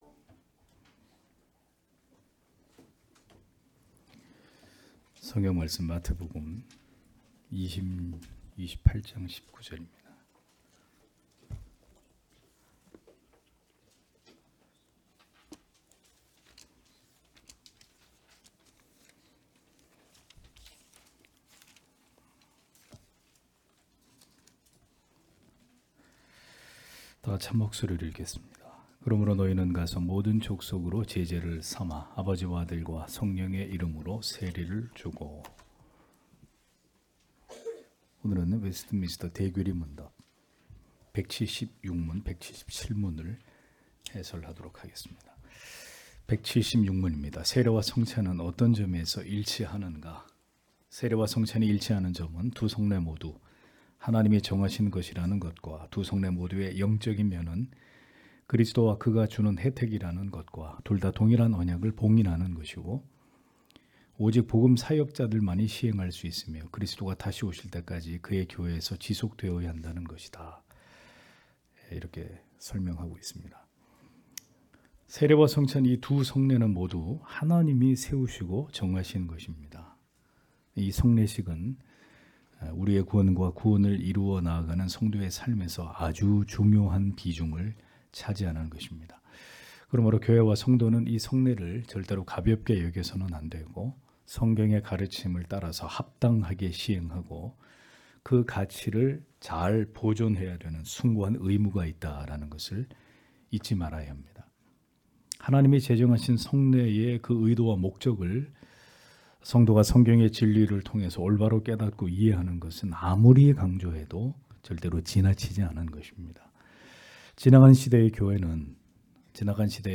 * 설교 파일을 다운 받으시려면 아래 설교 제목을 클릭해서 다운 받으시면 됩니다.